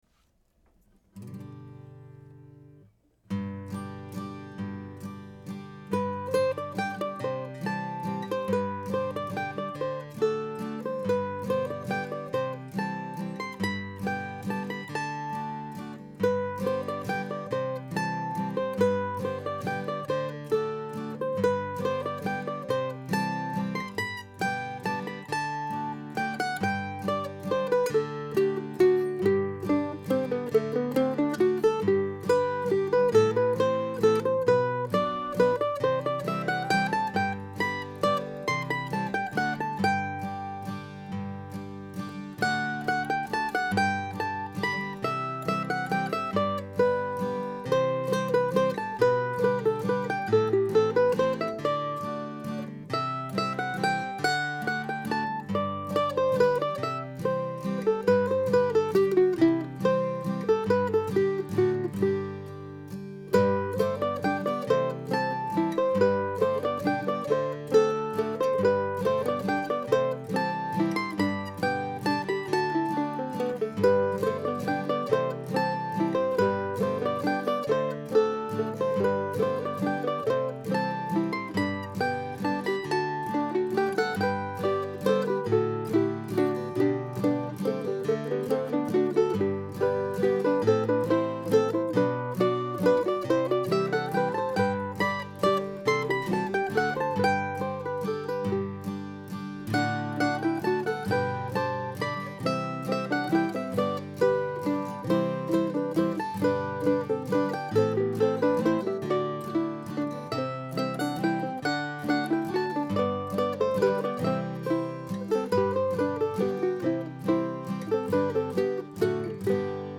I also recorded a basic guitar track as a kind of continuo (and click track) for the mandolins in this recording.